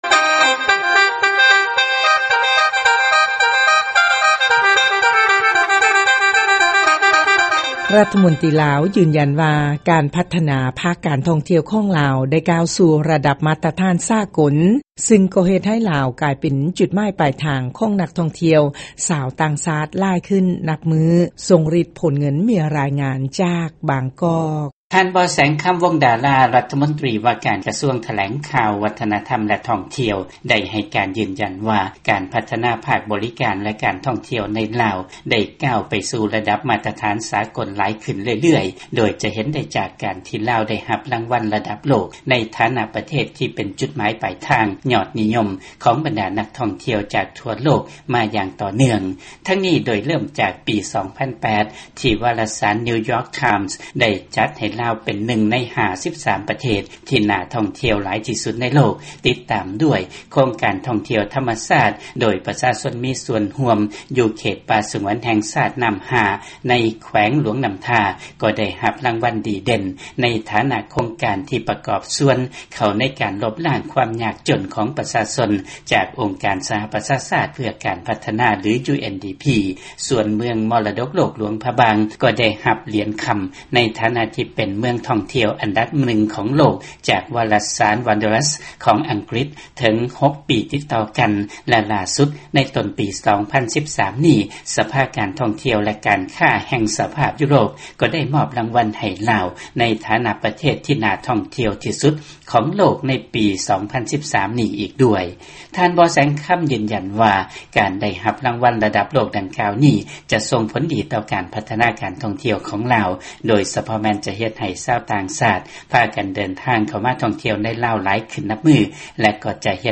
ຟັງລາຍງານການທ່ອງທ່ຽວລາວ